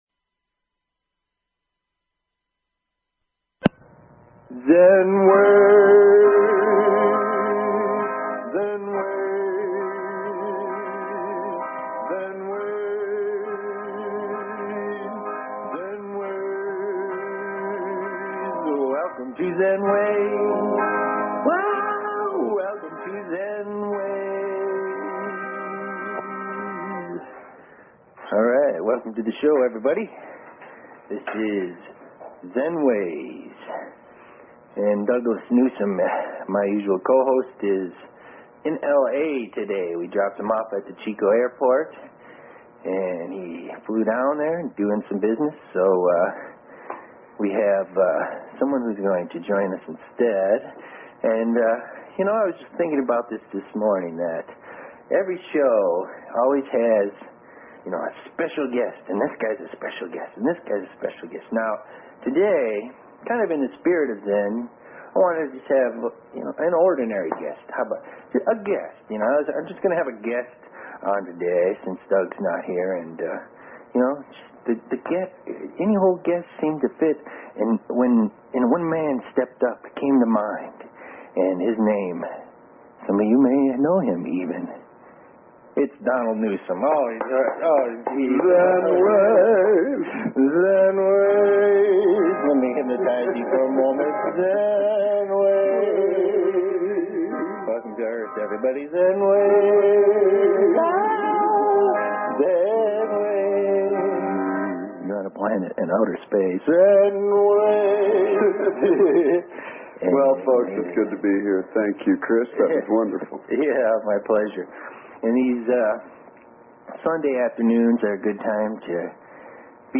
Talk Show Episode, Audio Podcast, Zen_Ways and Courtesy of BBS Radio on , show guests , about , categorized as